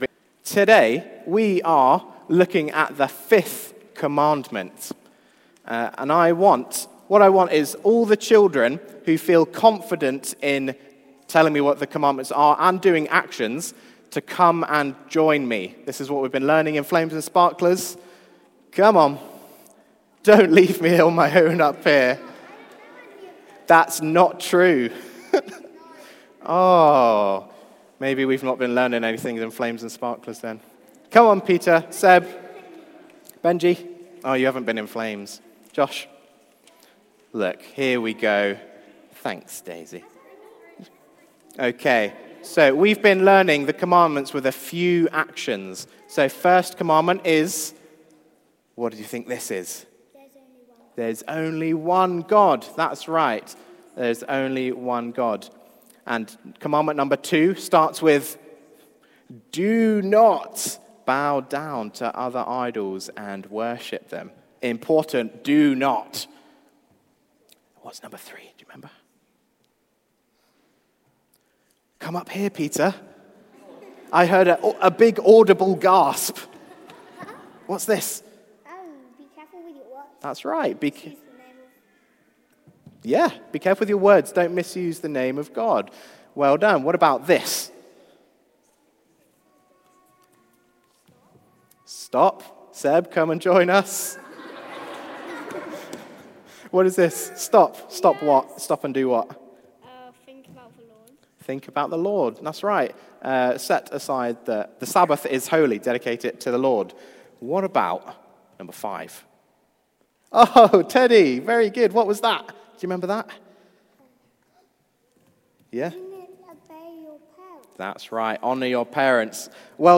Theme: Family Matters: Honouring your Parents Sermon